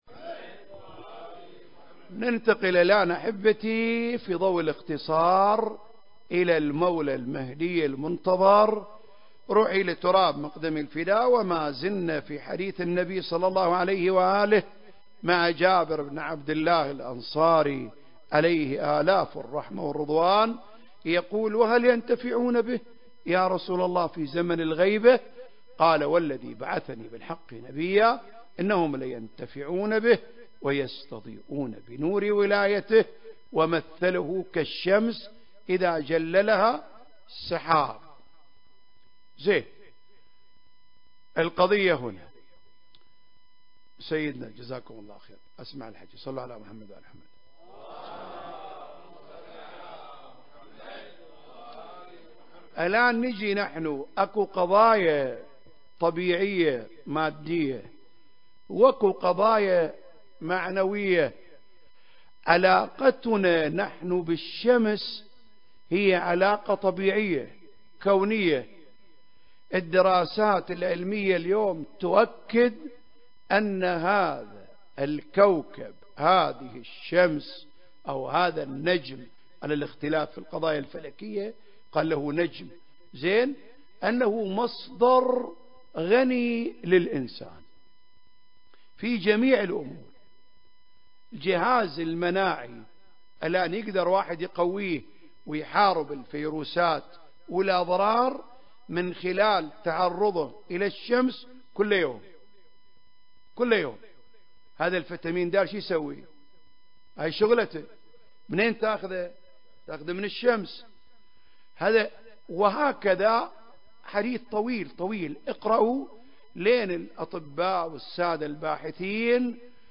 سلسلة محاضرات في رحاب الإمام صاحب الزمان (عجّل الله فرجه) (3) المكان: الحسينية الهاشمية/ الكويت التاريخ: 2023